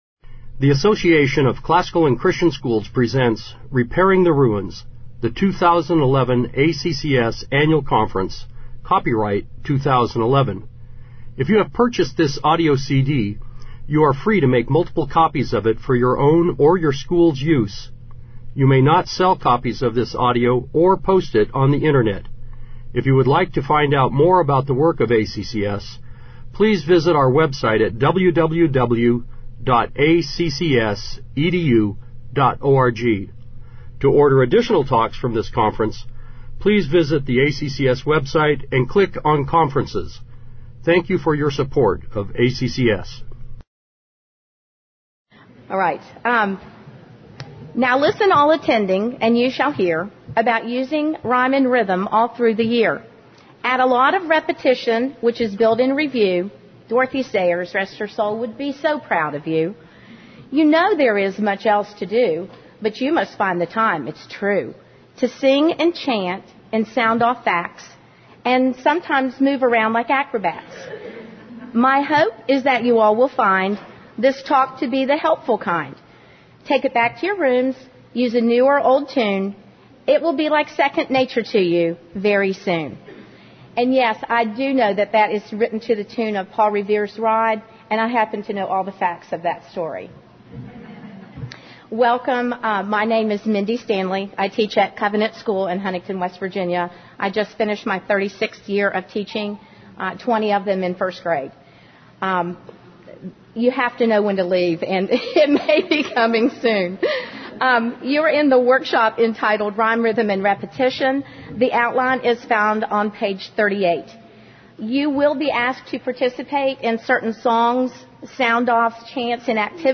2011 Workshop Talk | 0:56:42 | K-6, General Classroom
General Classroom Download Summary This workshop is designed for the grammar stage of the Trivium. It will demonstrate how to capitalize on the natural ability of children to memorize facts by using the three R’s. Participants will engage in singing, shouting, and sounding off.